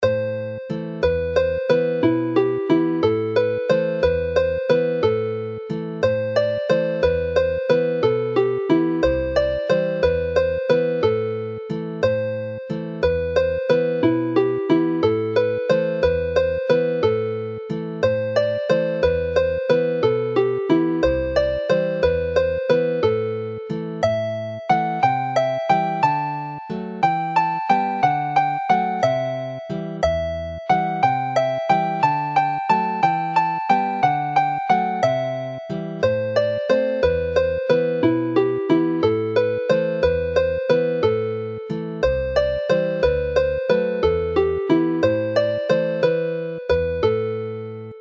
Skip jig based on the melody
Play the tune slowly